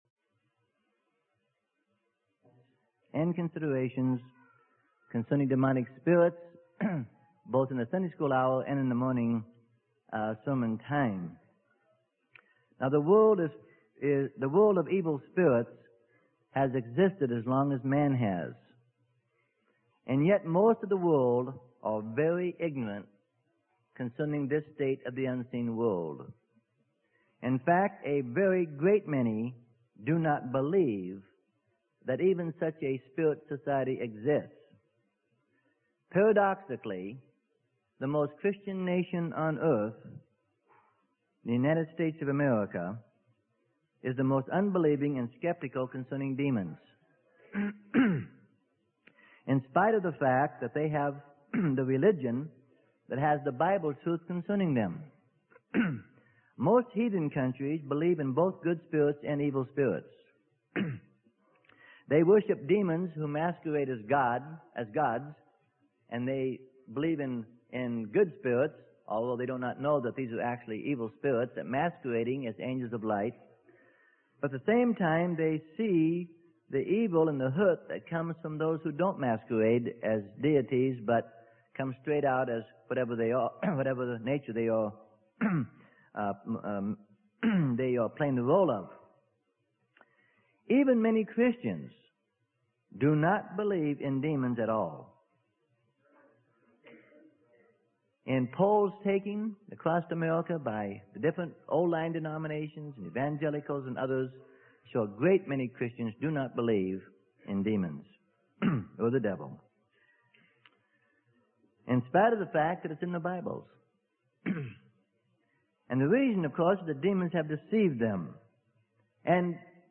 Sermon: Added Insight and Considerations Concerning Demon - Freely Given Online Library